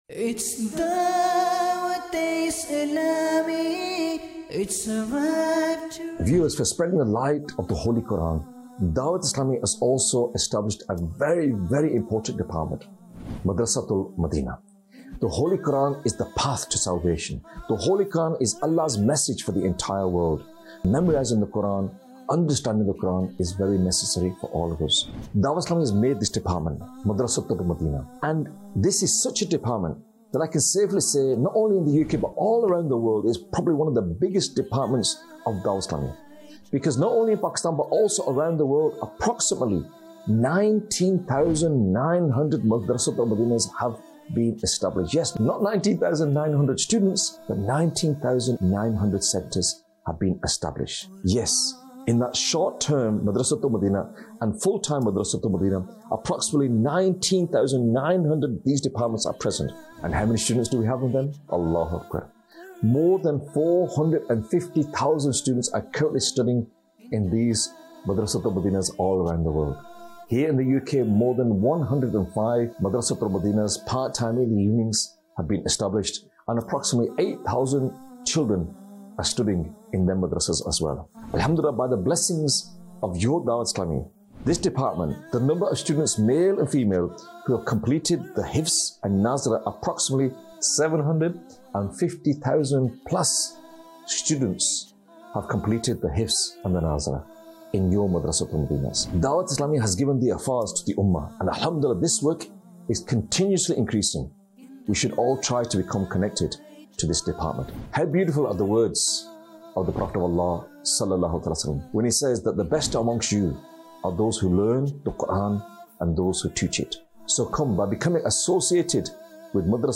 Madrasa Tul Madinah | Department of Dawateislami | Documentary 2026
Vocalist